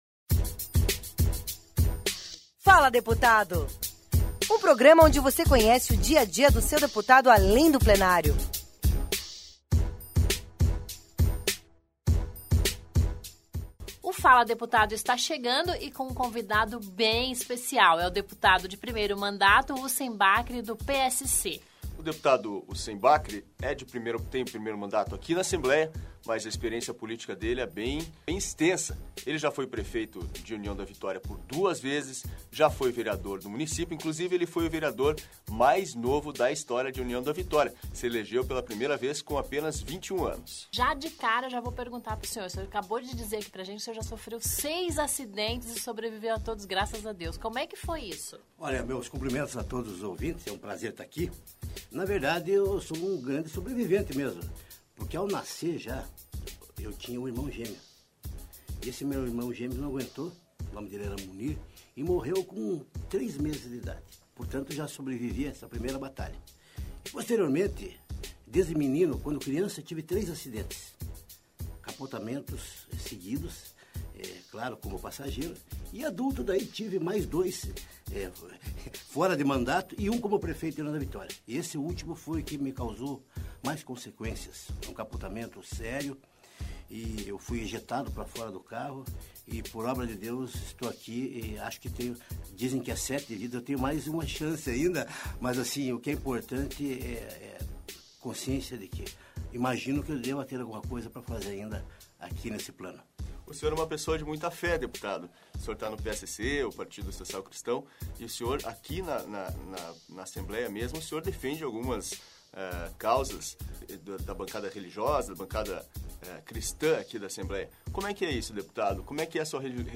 Ouça esta surpreendente e, ao mesmo tempo, irreverente entrevista com um dos muitos imitadores do ex-presidente Lula, no programa “Fala Deputado”, da Rádio Assembleia.